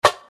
nt snare 12.wav